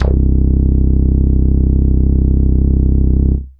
SYNTH BASS-2 0007.wav